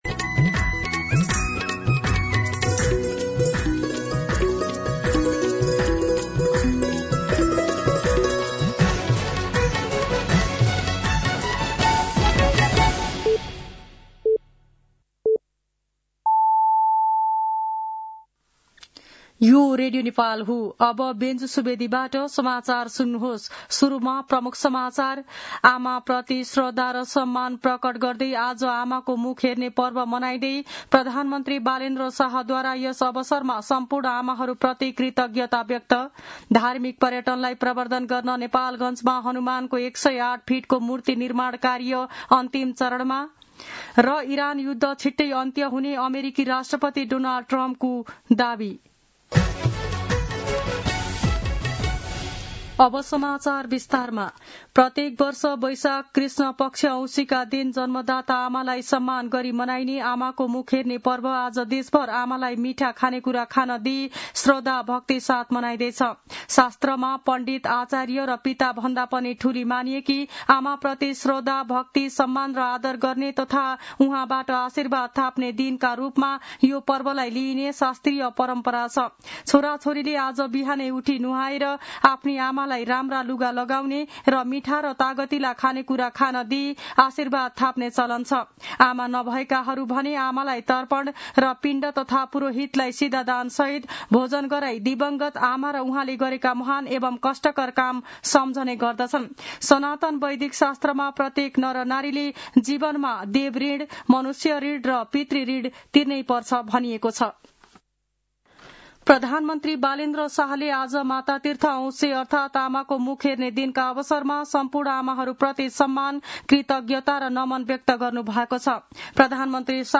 दिउँसो ३ बजेको नेपाली समाचार : ४ वैशाख , २०८३
3pm-News-1-4.mp3